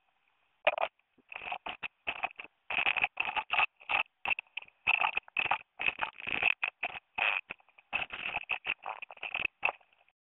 Scratching and Rustling: The Noises of Nest Building and Foraging
These can vary based on the materials used, from the soft rustling of leaves to the sharper scratching of twigs and bark.
Scratching-and-Rustling.mp3